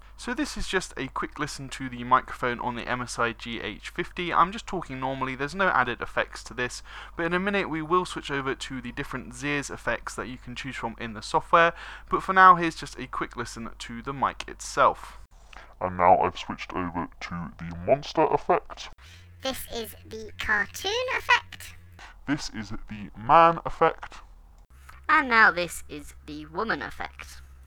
GH50 mic test
As for the unidirectional mic, generally speaking it sounds fine – it doesn't blow me away with how clear it is, and it is a little nasally, but I had no issues using the mic and certainly didn't hear any complaints from my team mates. The end of the recording does just go to show how silly those Xears effects are, though, and I obviously wouldn't recommend using those when gaming with friends.
gh50-mic-test.mp3